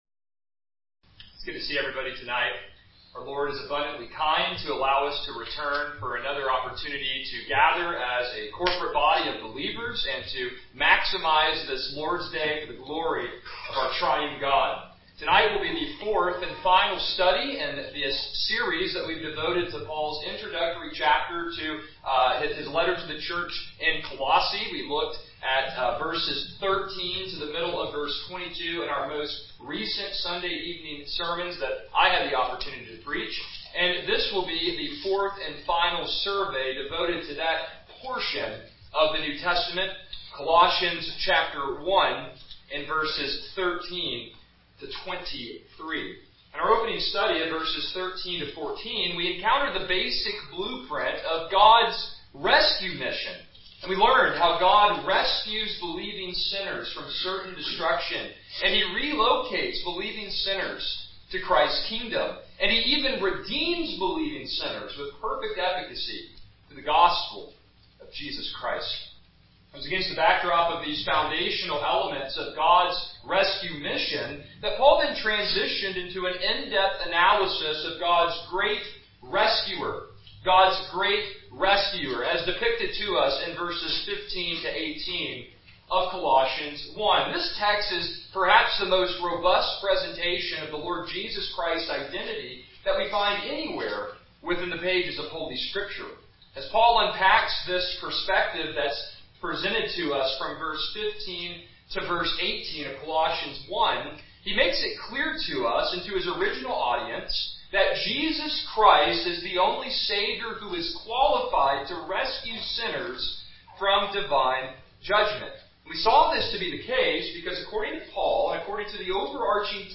Passage: Colossians 1:22-23 Service Type: Evening Worship